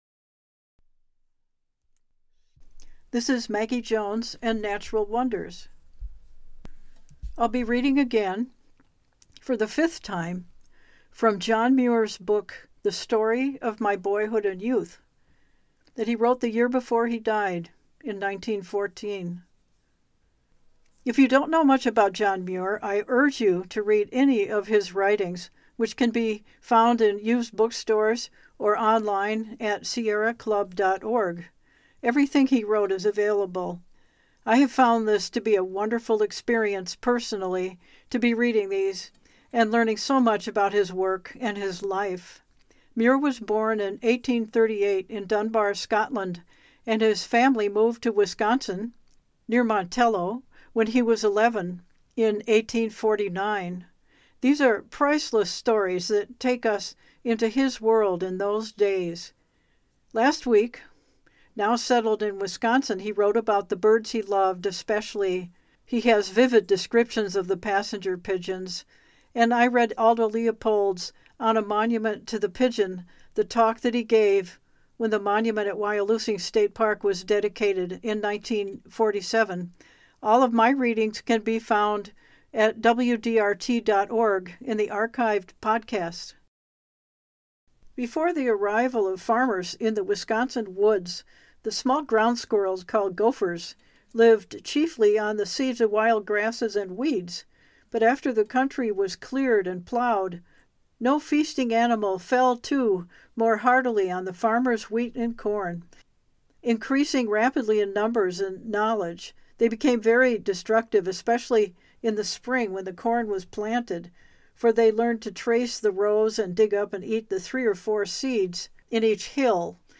We hear the 5th reading of The Story of My Boyhood and Youth by John Muir, written a year before he died in 1914. Farming the new wilderness through the seasons, the crops, the challenges, and the work it took, take center stage today.